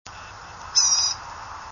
Blue-gray Gnatcatcher
Polioptila caerulea / Gobe-moucherons gris-bleu
Blue-gray Gnatcatcher Blue-gray Gnatcatcher Polioptila caerulea / Gobe-moucherons gris-bleu Campground behind Horseshoe Cove, Sandy Hook, 4/25/03, 1:00 p.m. (7kb) wave723 Index
gnatcatcher_blue-gray_723.wav